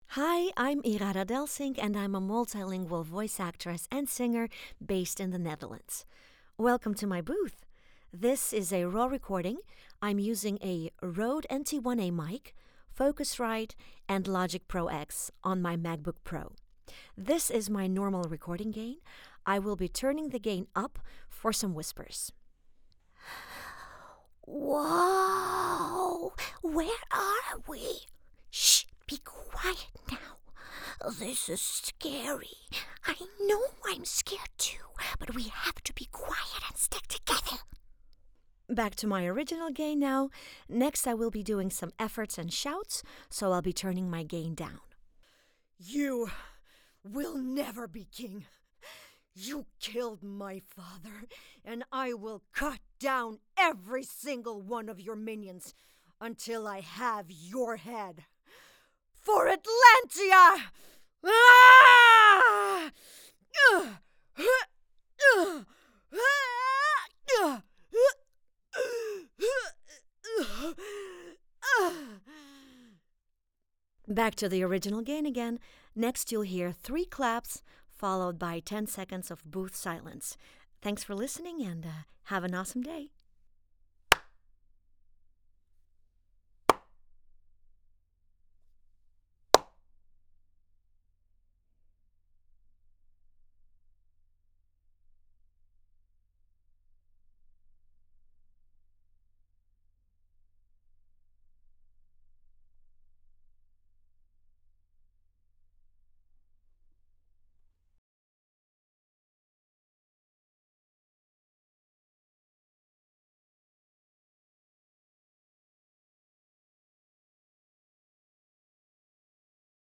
I work from my professional home recording studio; so rest assured: high quality audio, quick turn-arounds and free custom samples guaranteed.
English demo Dutch general demo Raw Studio Sample 2025 CHAMELEON Looking for a Russian voiceover – look no further!
Studio/recording specs: Acousticly sound proof booth Focusrite interface Scarlett Studio Pro mic Røde NT1-A AKG Lyra Logic Pro X LG IPS LED MacBook Air
Raw-Studio-Sample-2025-new.mp3